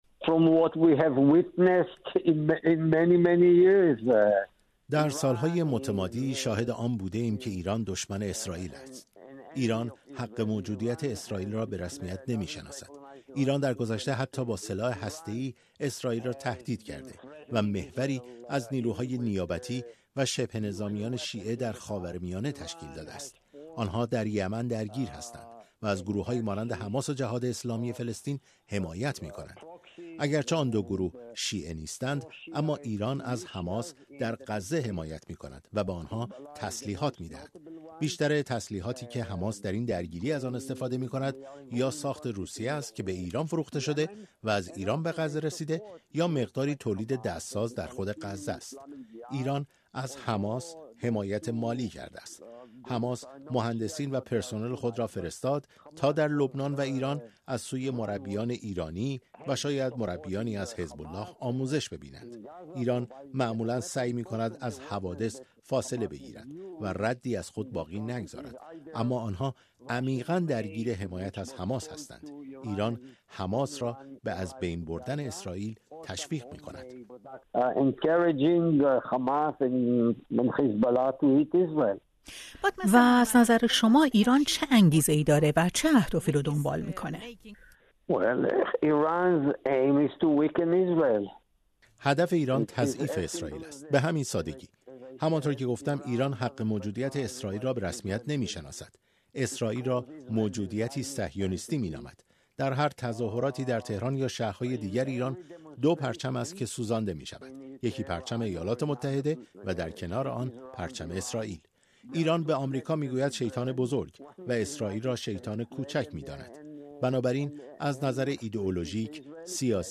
ایران و حمله حماس به اسرائیل؛ گفت‌وگو